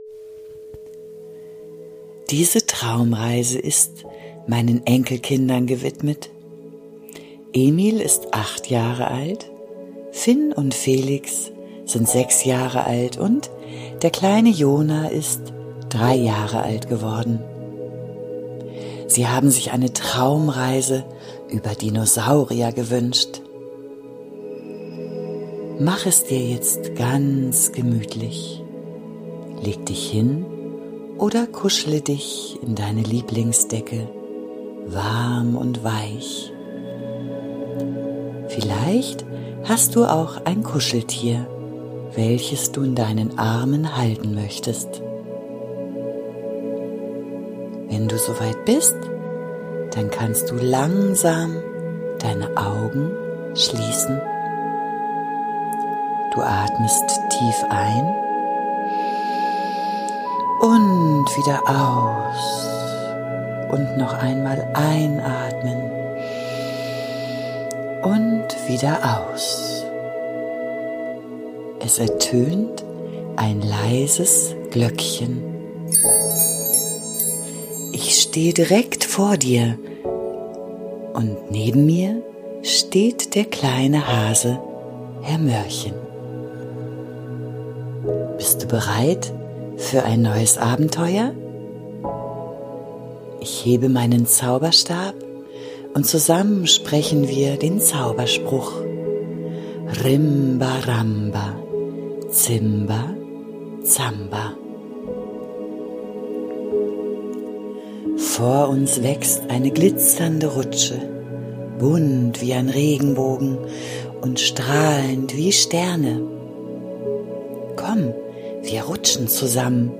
Eine liebevoll erzählte Fantasiereise voller Geborgenheit,